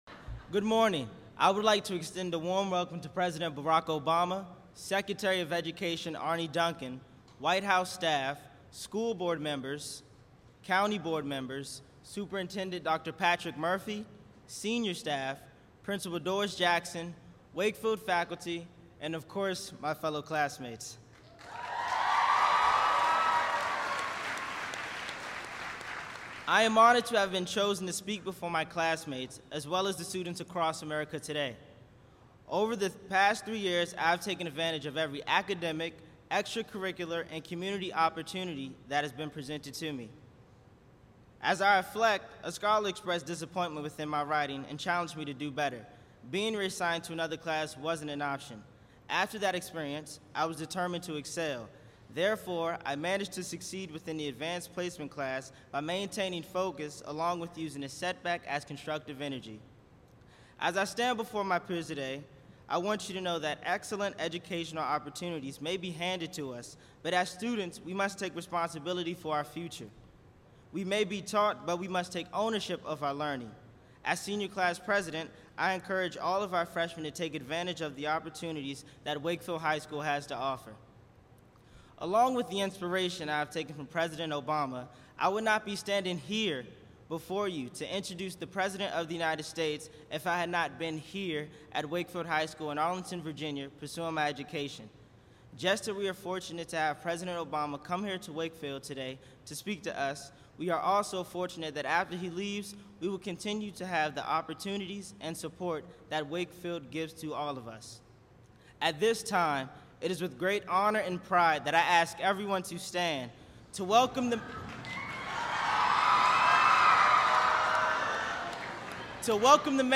Complete text transcript and audio mp3 and video of Barack Obama Nationwide Speech to America's Students
barackobamabacktoschoolspeechstudents.mp3